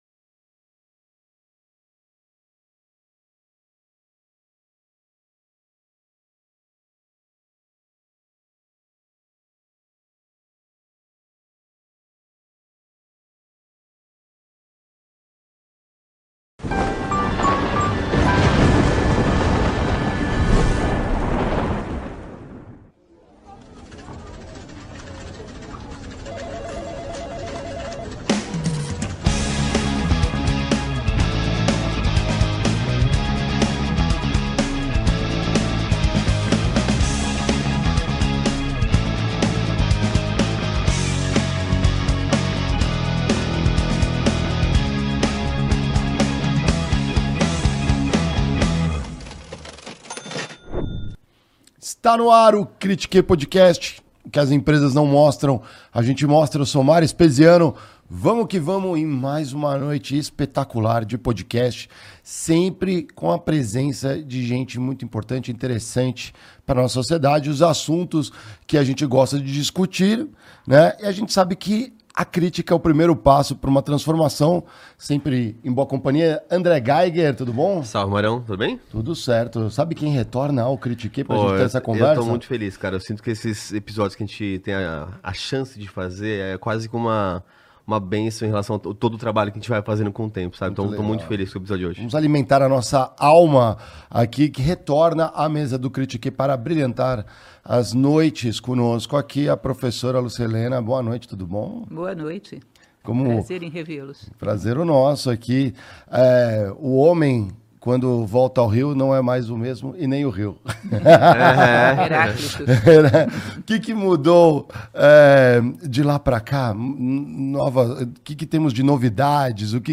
Conférence de la série "les premiers philosophes"
Enregistrée à l'Espace Le Moulin, Paris, le 21 décembre 2023